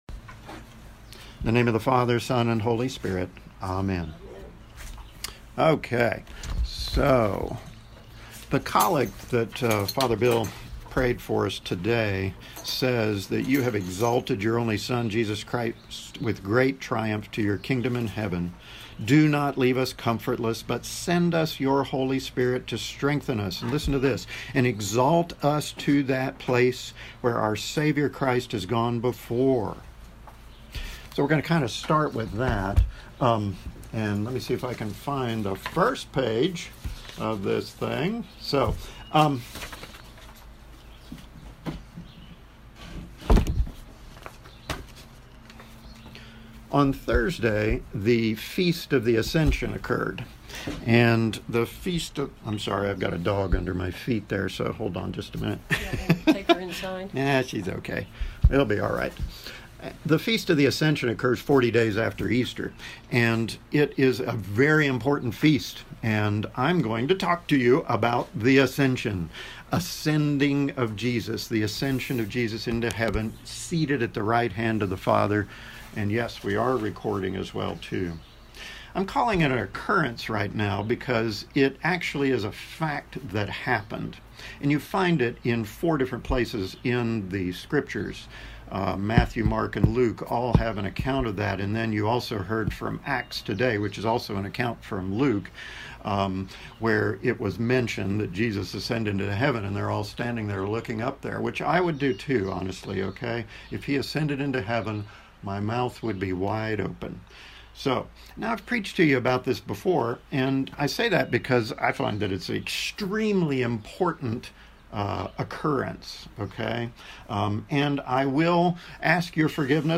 Sermons - Church of the Resurrection CEC